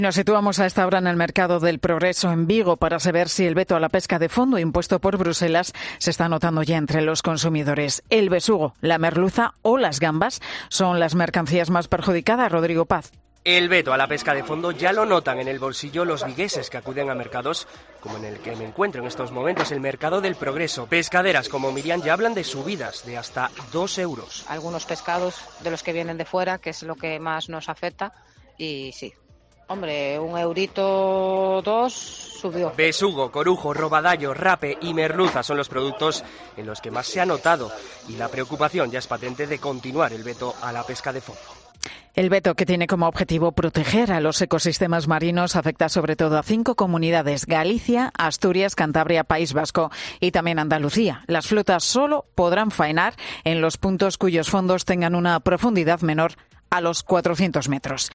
COPE ha comprobado en el Mercado del Progreso de Vigo como los pescados más consumidos han visto incrementado su precio